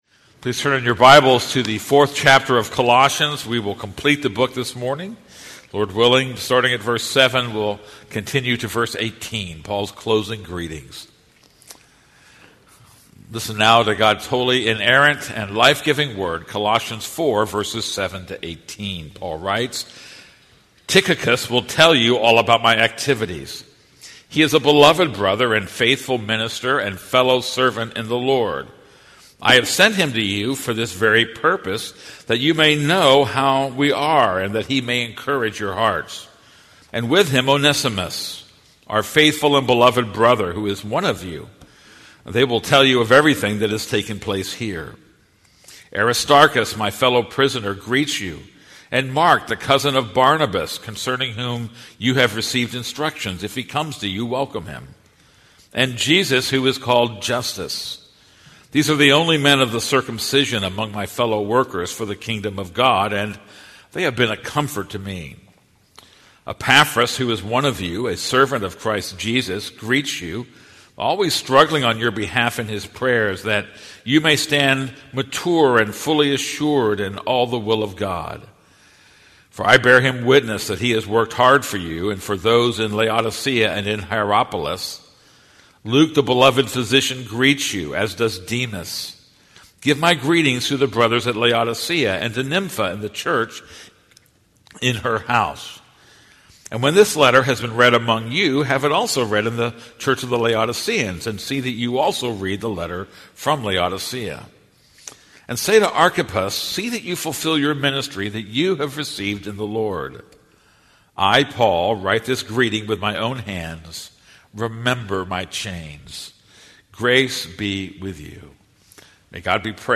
This is a sermon on Colossians 4:7-18.